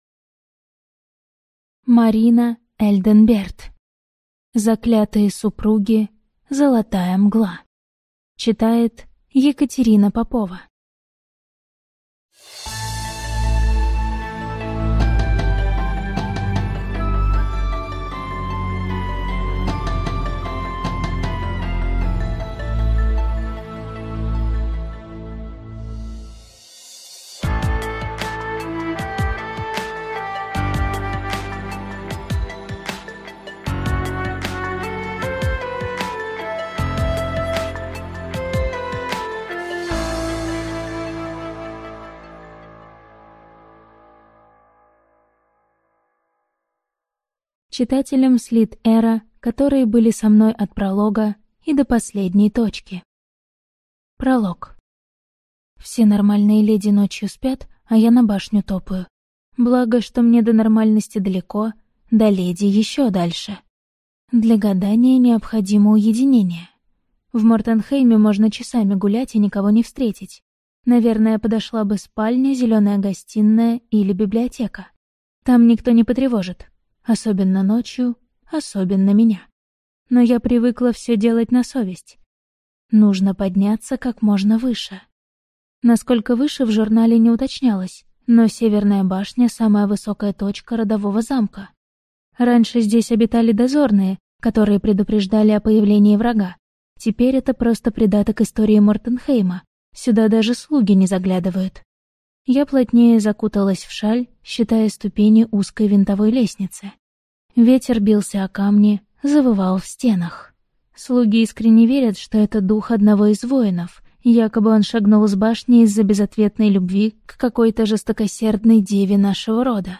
Аудиокнига Заклятые супруги.